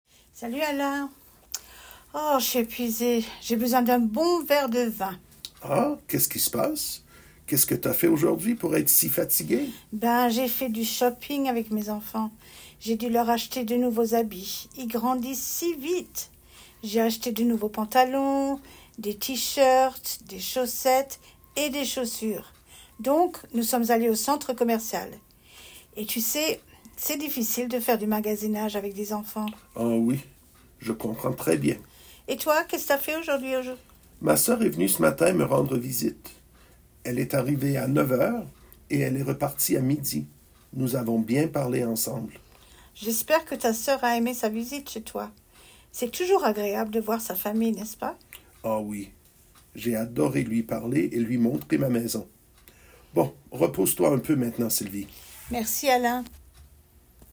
Dialogue – Un 9 | FrenchGrammarStudio